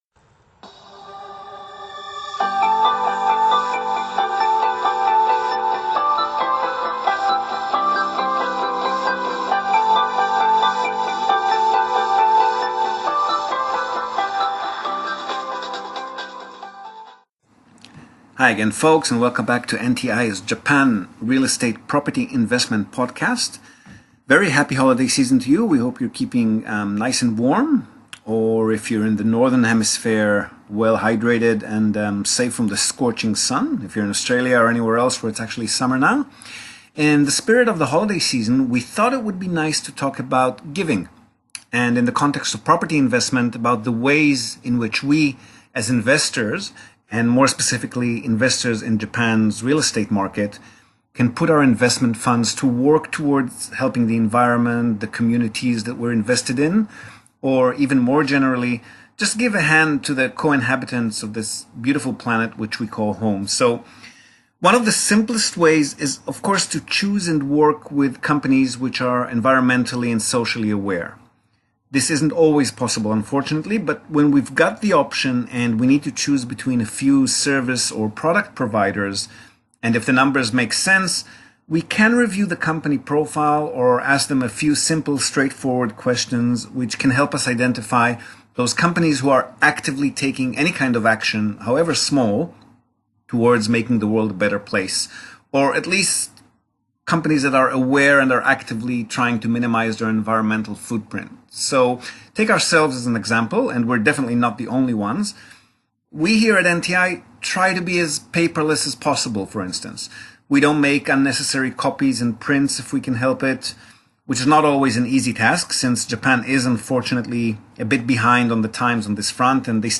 We talk to a new client from Canada, in the process of examining his long-term purchase and portfolio structuring options (cash/financed, units/buildings, locations etc).